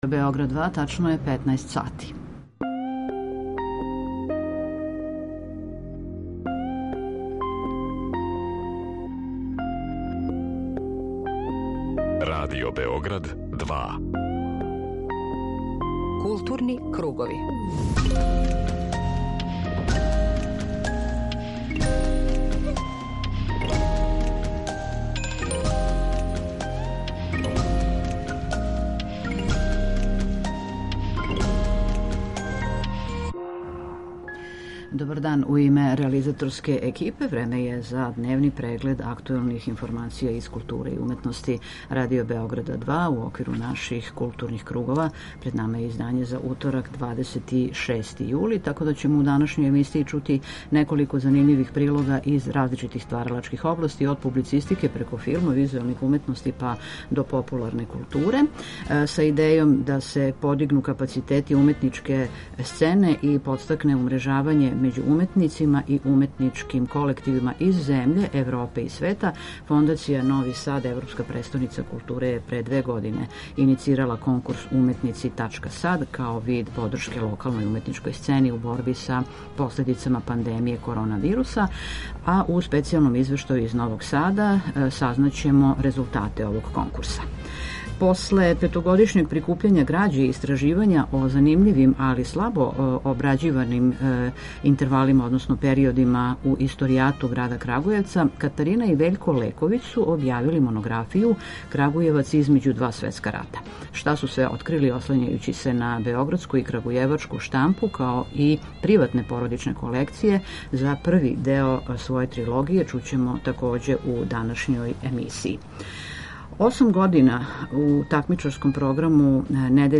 Дневни културно-информативни магазин
У специјалном извештају из Новог Сада сазнаћемо резултате конкурса.